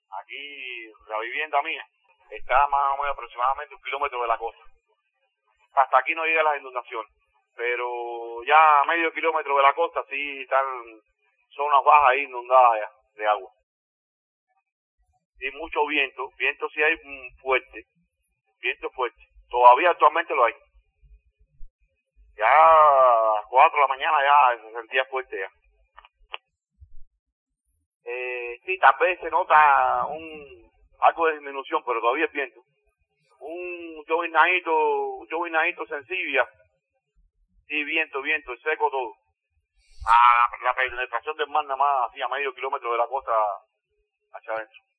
A medio kilómetro de la costa está todo inundado: residente en Batabanó